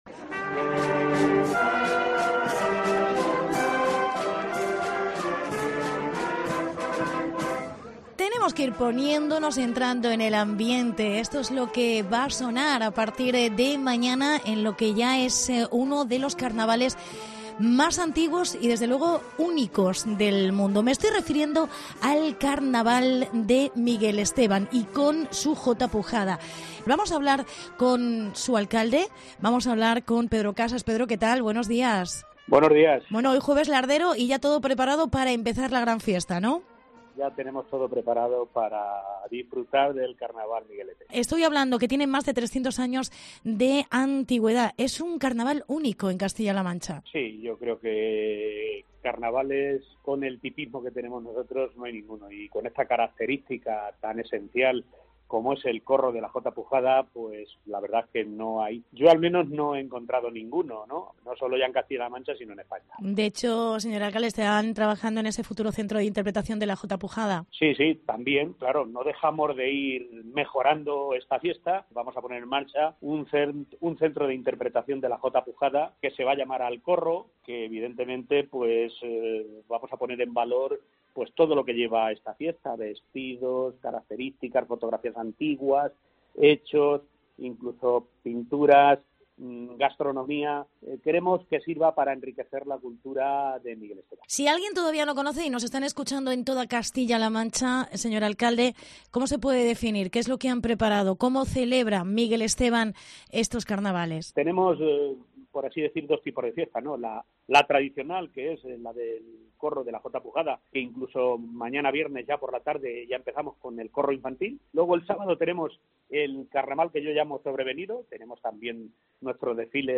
Entrevista Pedro Casas. Alcalde de Miguel Esteban